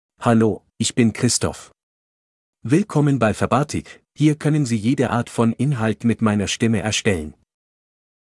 ChristophMale German AI voice
Christoph is a male AI voice for German (Germany).
Voice sample
Listen to Christoph's male German voice.
Male
Christoph delivers clear pronunciation with authentic Germany German intonation, making your content sound professionally produced.